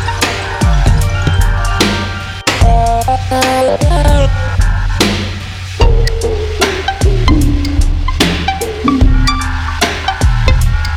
Category: Electro RIngtones